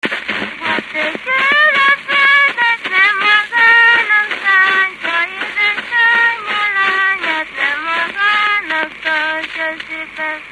Dallampélda: Az ökör a földet
Dallampélda: Az ökör a földet Erdély - Kolozs vm. - Méra Gyűjtő: Balabán Imre Gyűjtési idő: 1912.